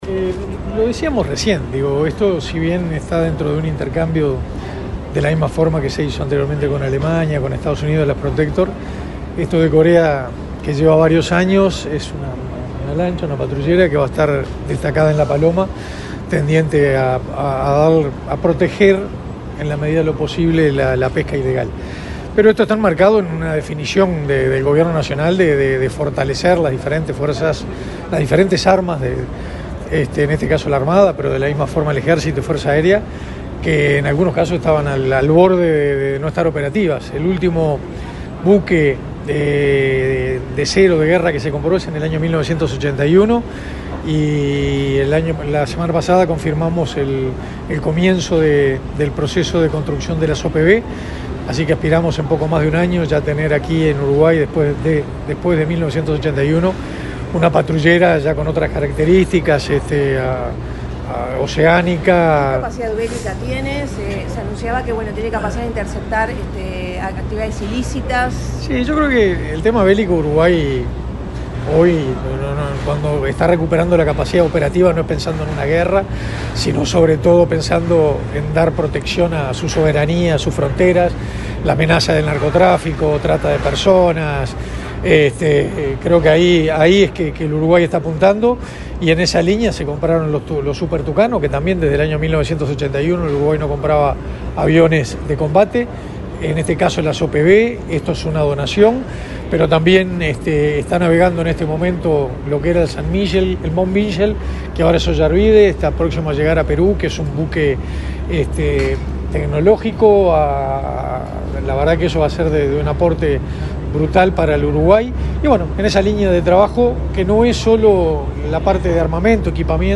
Declaraciones del ministro de Defensa Nacional, Armando Castaingdebat
Este lunes 21 en el puerto de Montevideo, el ministro de Defensa Nacional, Armando Castaingdebat, dialogó con la prensa, luego de participar en el